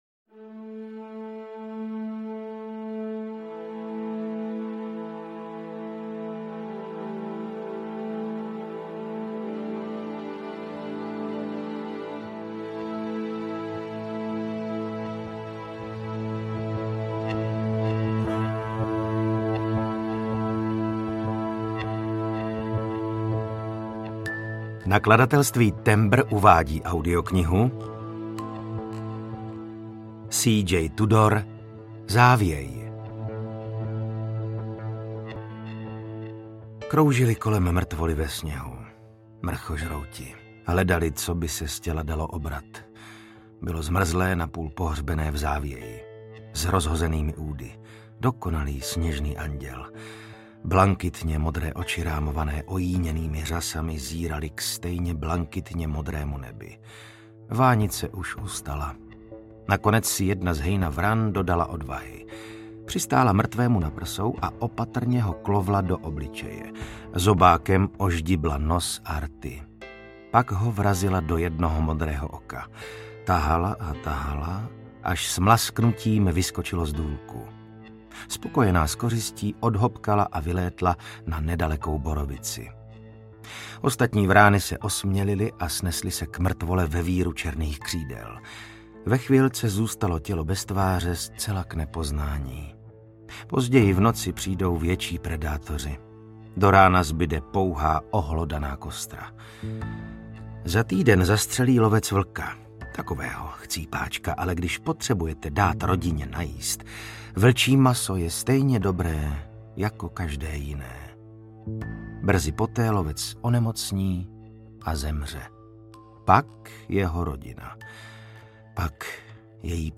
Závěj audiokniha
Ukázka z knihy
Natočeno ve studiu S Pro Alfa CZ